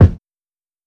Kick (Is There Any Love).wav